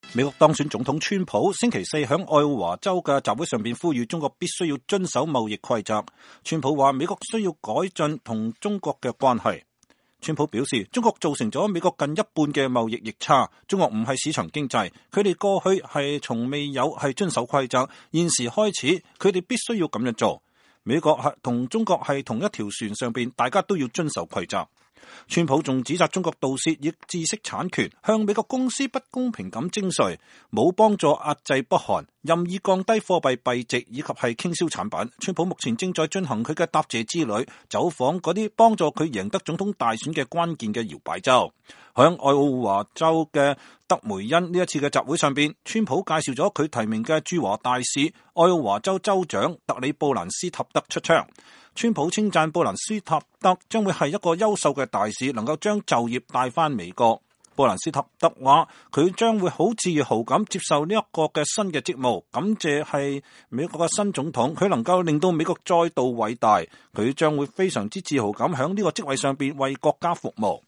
美國當選總統川普星期四在愛奧華州的集會上呼籲中國必須遵守貿易規則。
在愛奧華德梅因的這次集會上，川普介紹他提名的駐華大使、愛奧華州長特里.布蘭斯塔德出場。